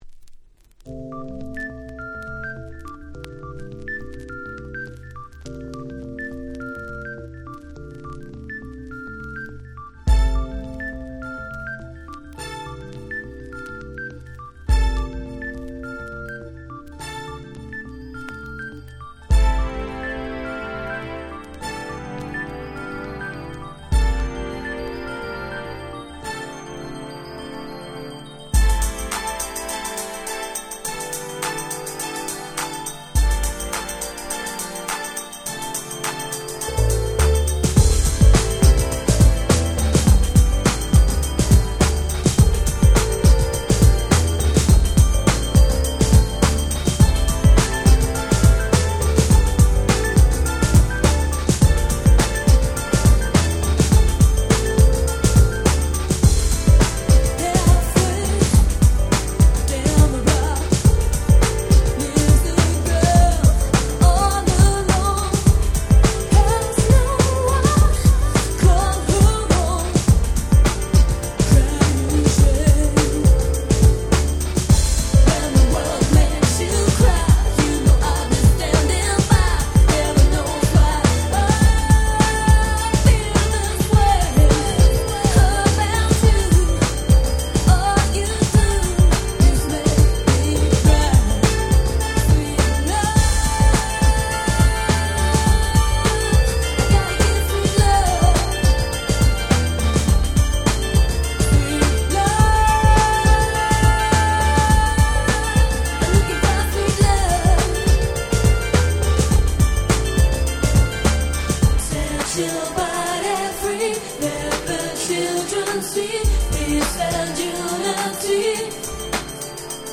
90' Very Nice Ground Beat !!
Ground Beat Classics !!
Funkyな女性Vocalが堪らない良質グラウンドビート！